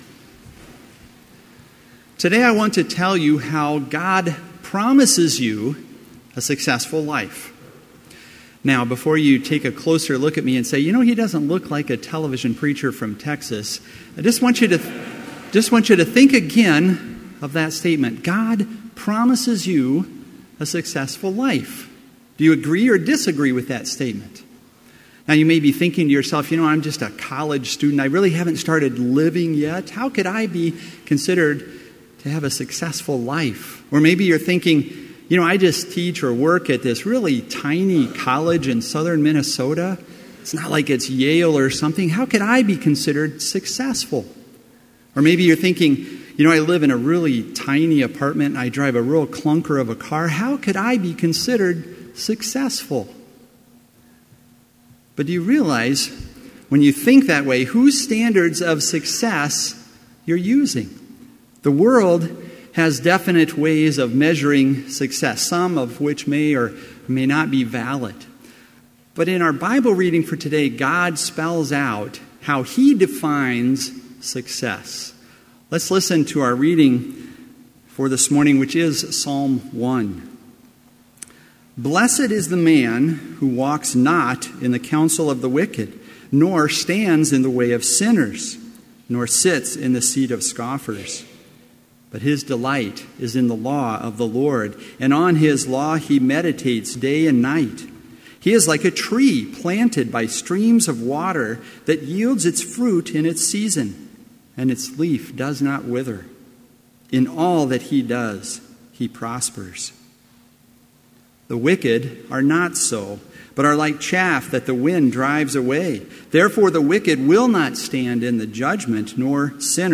Complete Service
• Prelude
• Devotion
This Chapel Service was held in Trinity Chapel at Bethany Lutheran College on Tuesday, September 5, 2017, at 10 a.m. Page and hymn numbers are from the Evangelical Lutheran Hymnary.